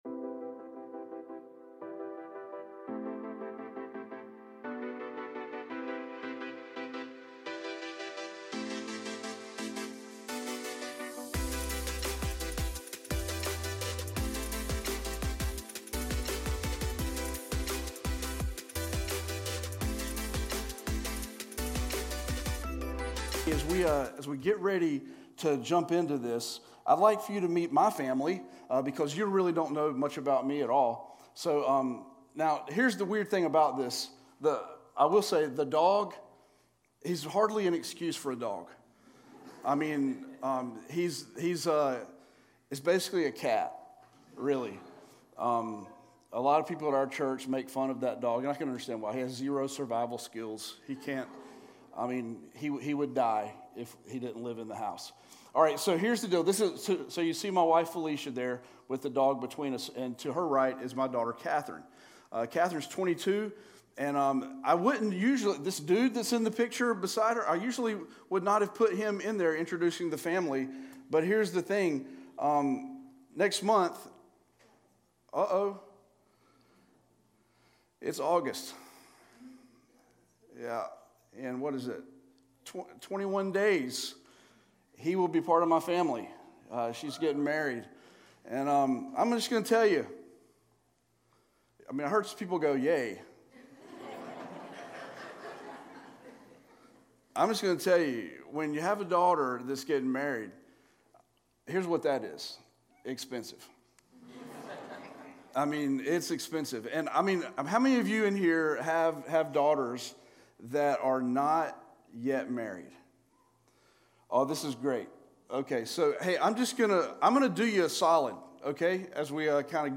A message from the series "One Off Messages."